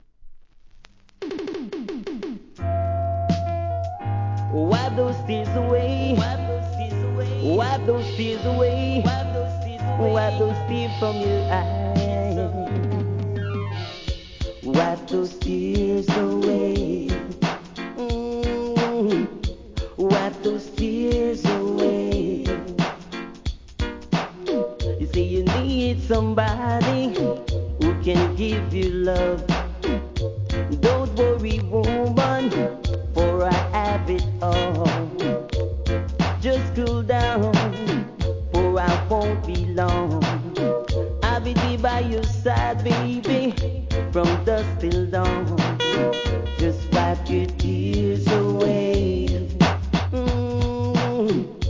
REGGAE
'80s DIGI-LOVERS ROCK!!